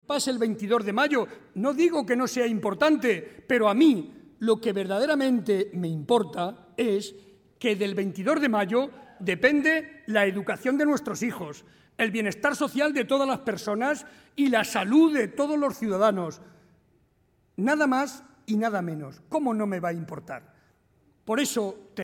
El presidente de Castilla-La Mancha y candidato a la reelección, José María Barreda, fue recibido por unas 1.100 personas en el Teatro Quijano de Ciudad Real a gritos de «presidente, presidente», donde advirtió de la importancia de las próximas elecciones, ya que, dijo, «nos jugamos el futuro de nuestro sistema sanitario, la educación de nuestros hijos y el bienestar de nuestros mayores».